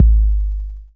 808 JEEP.wav